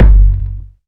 Kicks
KICK.25.NEPT.wav